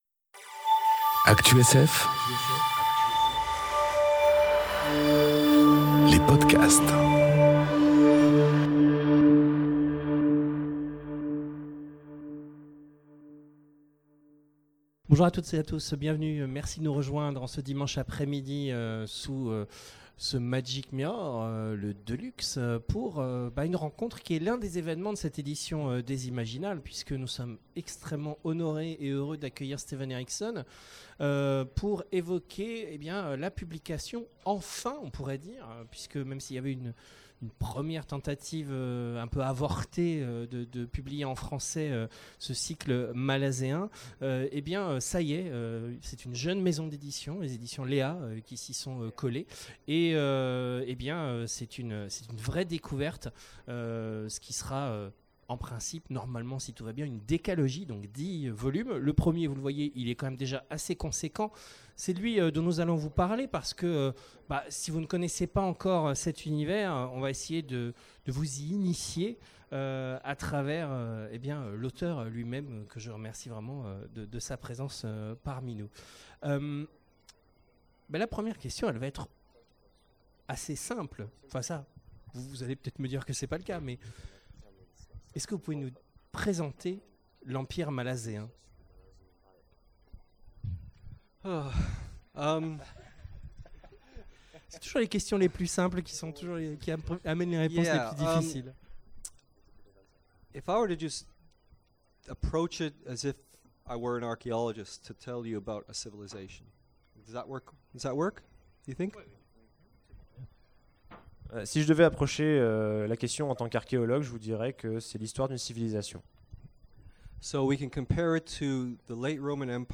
Imaginales 2018 : Entretien avec Steven Erikson
Entretien avec Steven Erikson enregistré aux Imaginales 2018
Rencontre avec un auteur